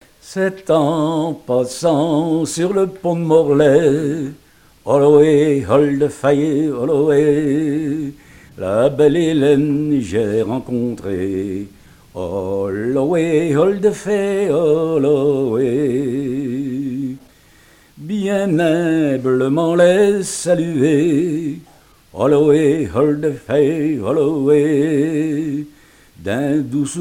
Mémoires et Patrimoines vivants - RaddO est une base de données d'archives iconographiques et sonores.
chansons dont de marins
Pièce musicale inédite